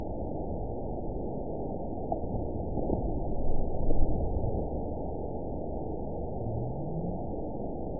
event 922846 date 04/19/25 time 02:14:04 GMT (1 month, 3 weeks ago) score 9.63 location TSS-AB02 detected by nrw target species NRW annotations +NRW Spectrogram: Frequency (kHz) vs. Time (s) audio not available .wav